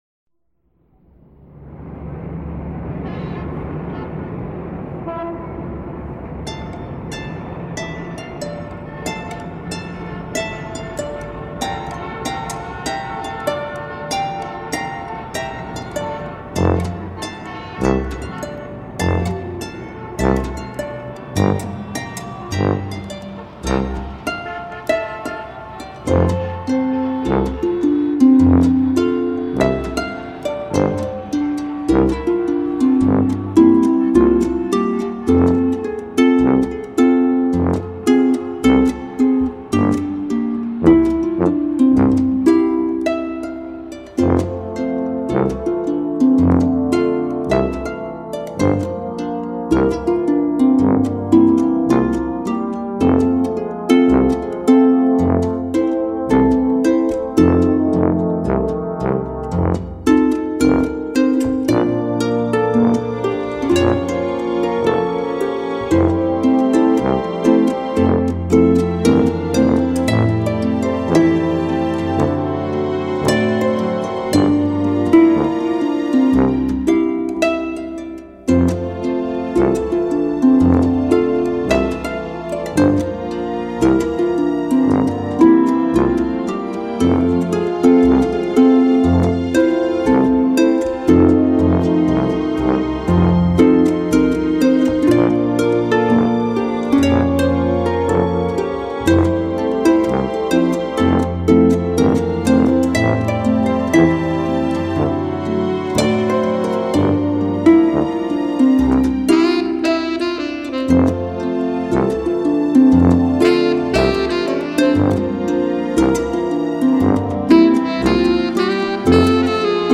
令人沉醉不已的豎琴音樂，謝謝分享